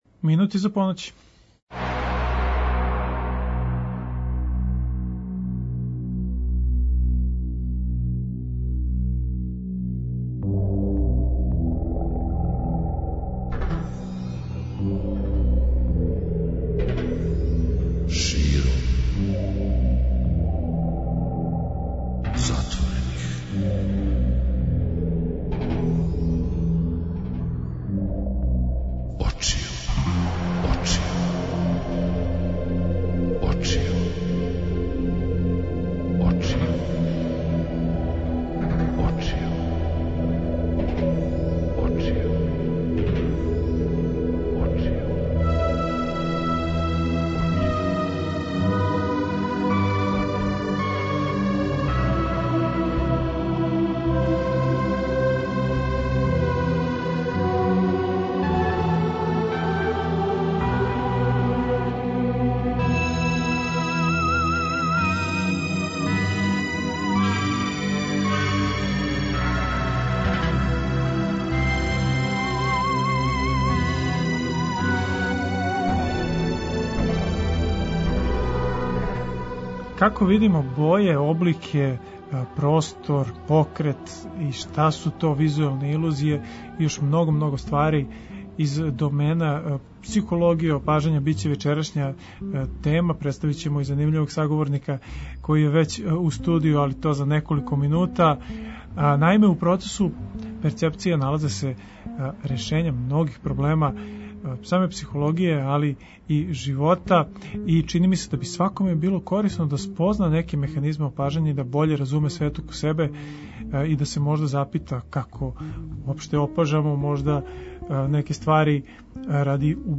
Наш саговорник ће нас увести у тај занимљив свет психологије опажања, а теме које се нижу биће како видимо боје, облике, простор, покрет, визуелне илузије при чему се отворено можете надовезати на било коју од тема, постављањем питања.